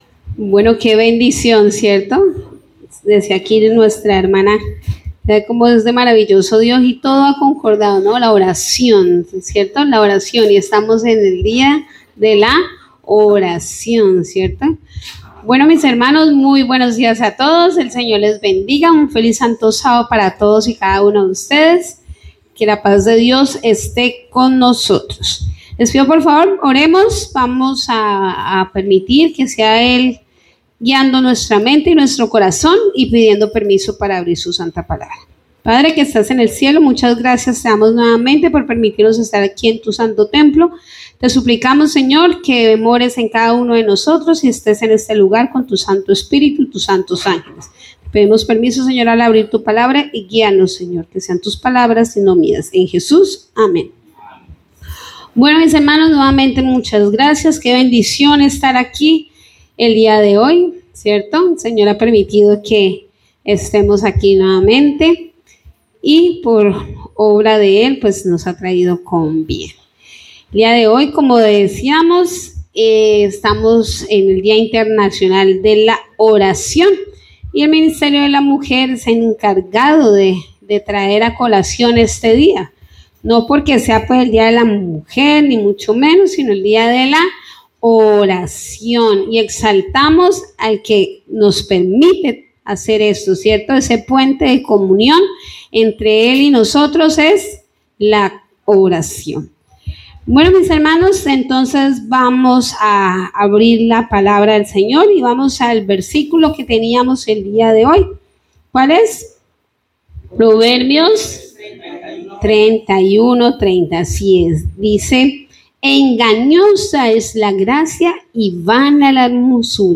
Predicación Primer Semestre 2026